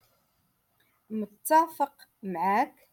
Moroccan Dialect - Rotation Two- Lesson Forty Eight